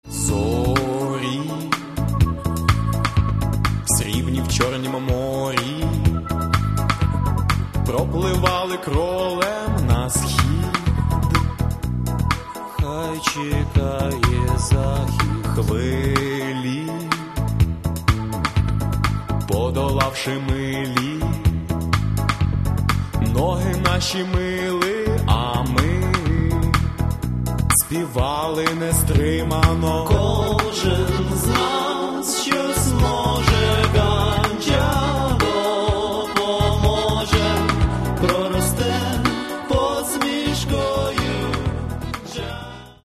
Каталог -> Рок и альтернатива -> Регги